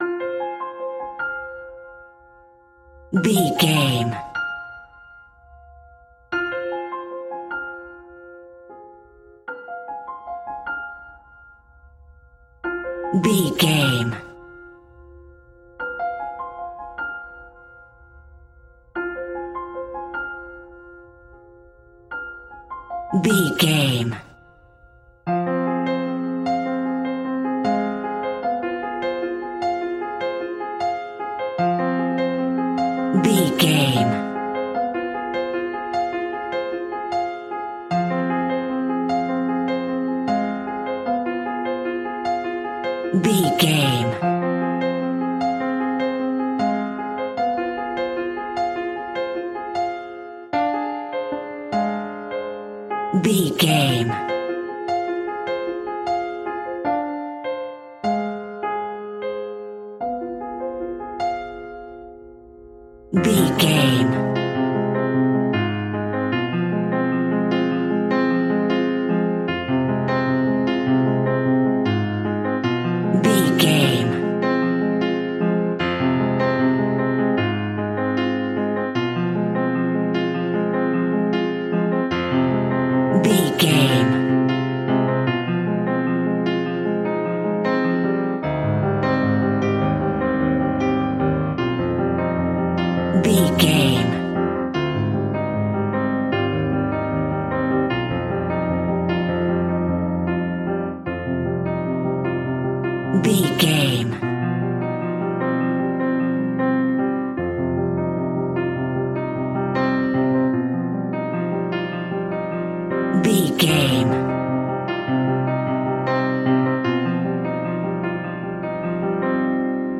Haunting Piano Music.
Aeolian/Minor
ominous
dramatic
eerie
horror
Acoustic Piano